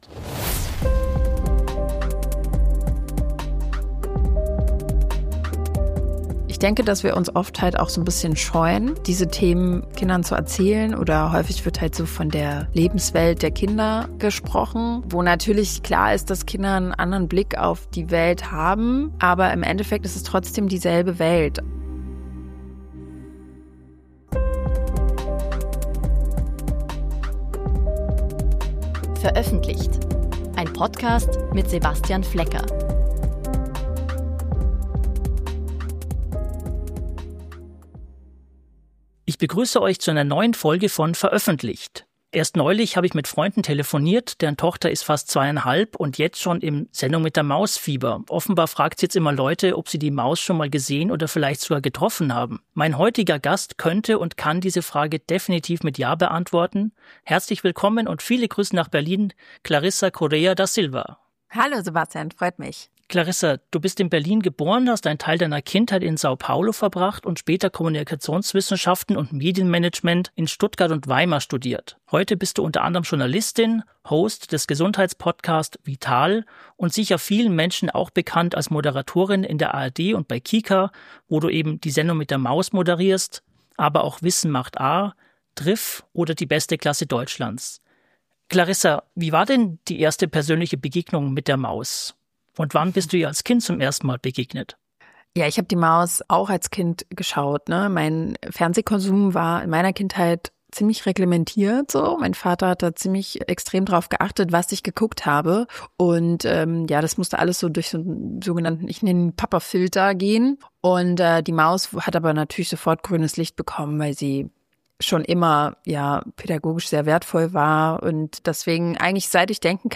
Ein Gespräch über Wissensvermittlung für Kinder, kreative Prozesse, gesellschaftliche Verantwortung – und die Rolle der Neugier.